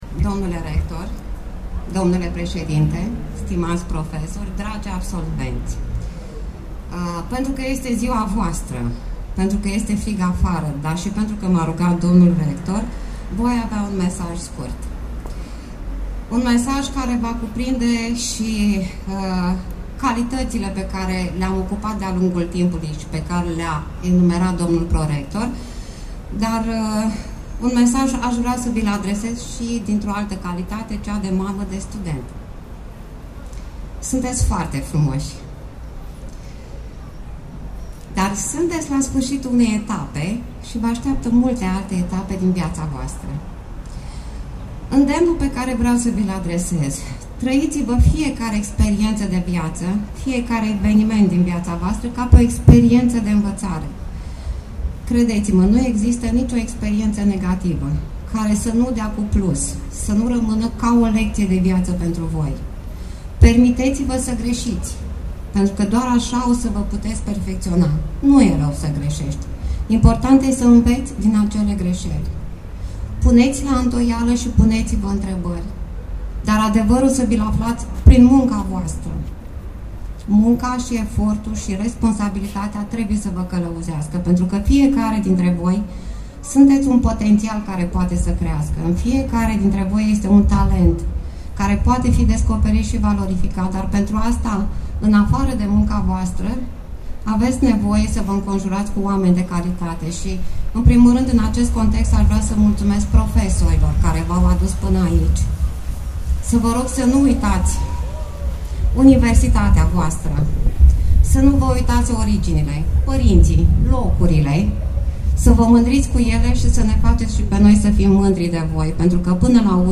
Absolvenții promoției 2017 au avut parte de cea mai frumoasă festivitate de absolvire, la care au fost prezenți profesori, părinți, prieteni și oficialități locale din Suceava.
discurs-Prefect.mp3